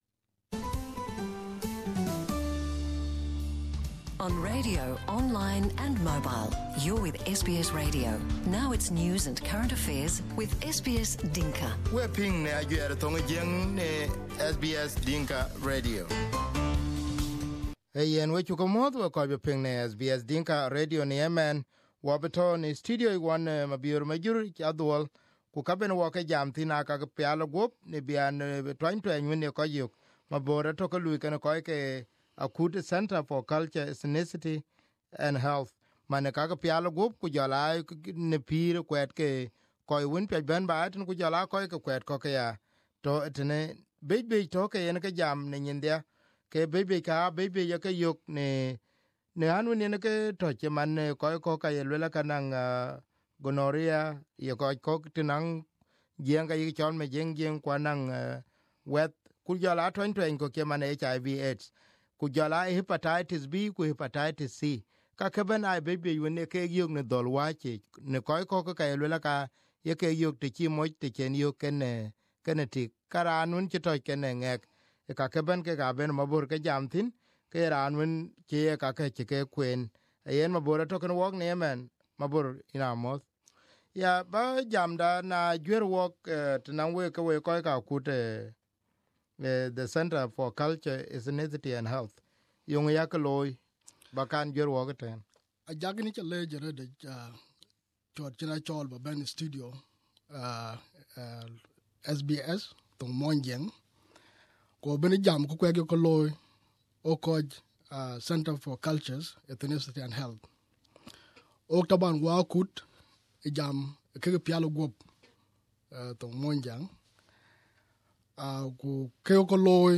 Here is the interview on SBS Dinka.